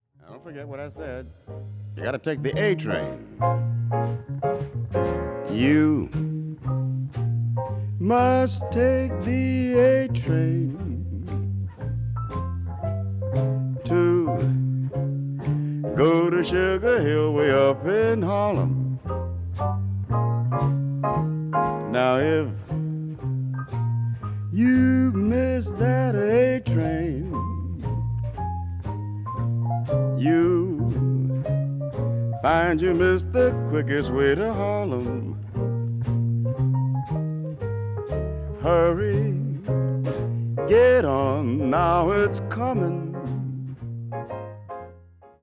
ラジオ番組のために録音された音源なのだそう。